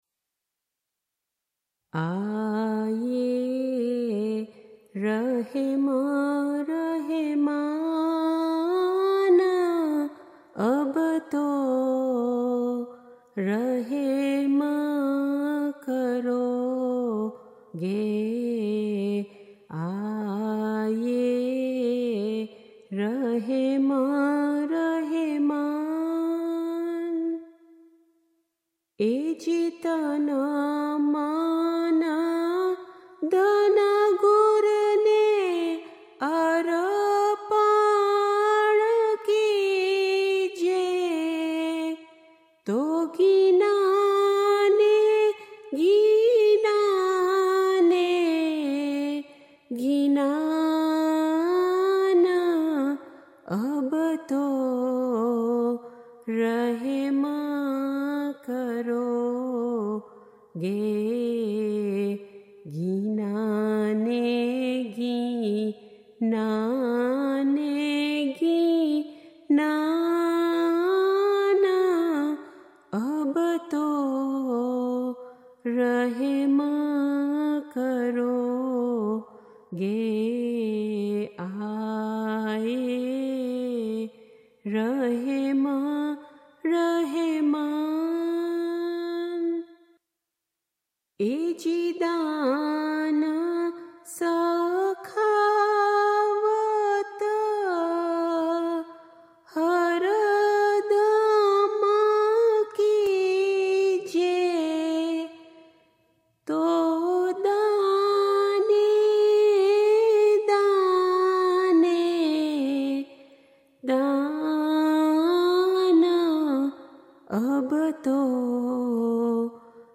Ginan: Aae rahem rahemaan – As Mercy is from you, O Merciful